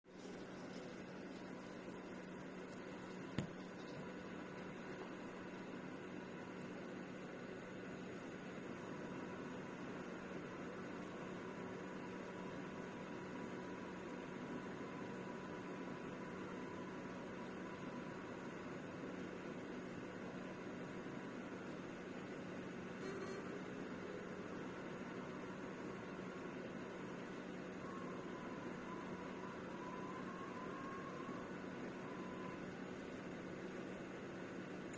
Problemy ze sprzętem dziwnie hałasuje podczas grania
To nie jest taki dźwięk to tak jakby piszczenie cewek albo coś jest nie tak z wentylatorem można wstawić tu nagranie? bo coś chyba robię źle chyba że wstawię tutaj linka do innego forum gdzie udało mi się wstawić nagranie?
pod koniec dobrze słychać
Zdjałem obudowe i wydaje mi sie z te dzwieki sa od wentylatora od procesora nie mogłem rozróznić dobrze miedzy wentylatorem od procesora czy karta graficzna